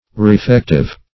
Meaning of refective. refective synonyms, pronunciation, spelling and more from Free Dictionary.
Search Result for " refective" : The Collaborative International Dictionary of English v.0.48: Refective \Re*fec"tive\ (r?*f?k"t?v), a. Refreshing; restoring.